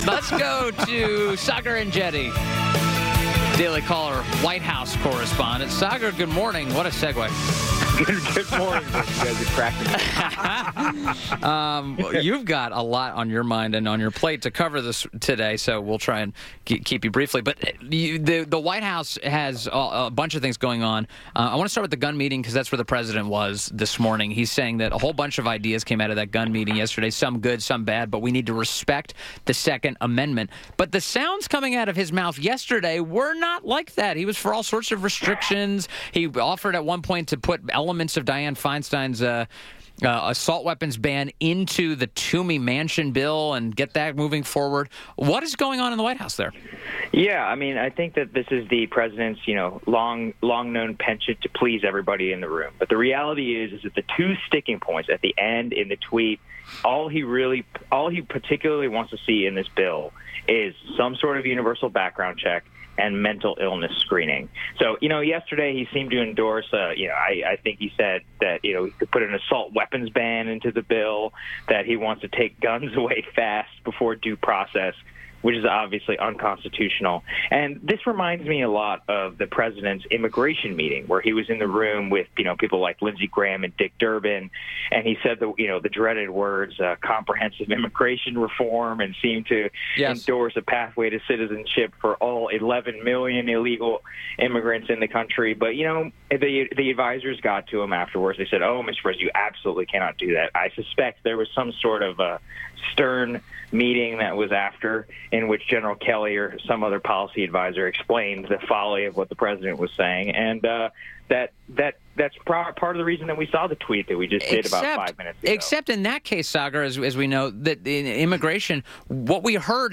WMAL Interview - SAAGAR ENJETI - 03.01.18